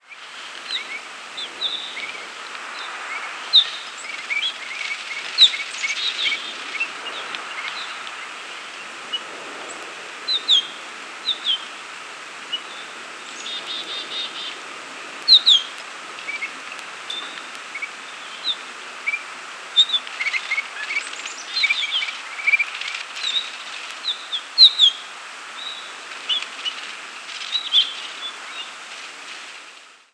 Pine Grosbeak diurnal flight calls
Perched bird giving "whit-whu-whee" then in flight giving "whit-whu-whee" and "hee-hee-hee".